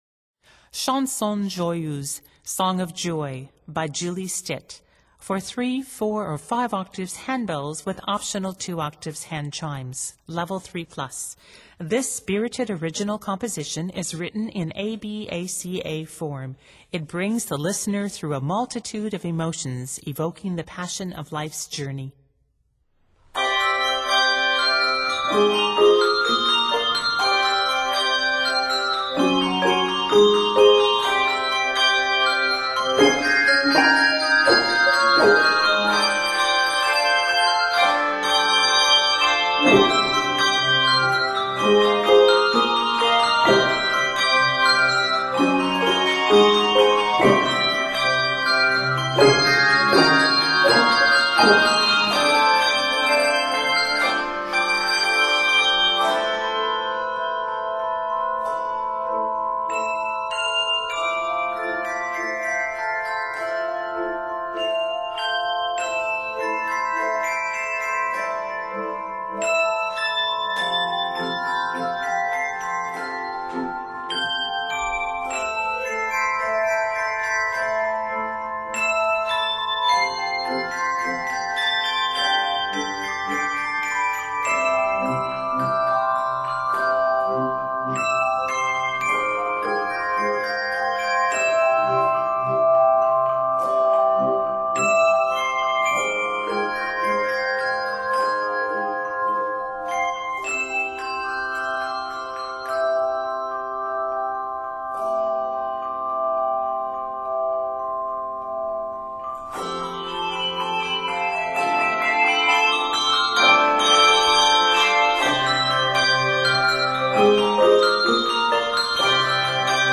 105 measures long, it is primarily scored in C Major.